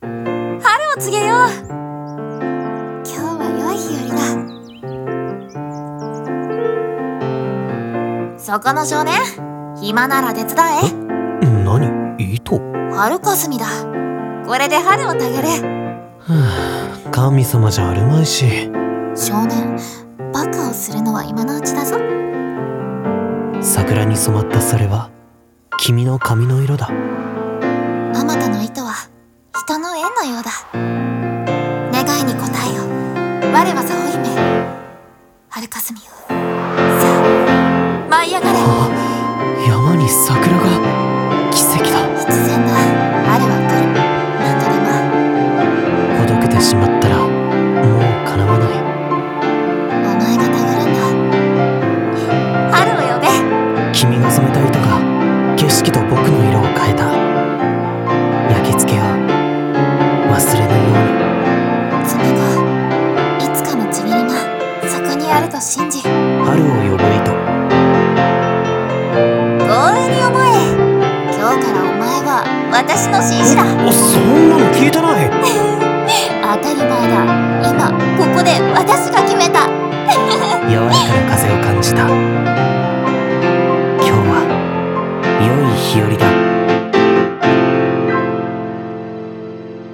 声劇【春を呼ぶ糸】